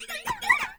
VOICES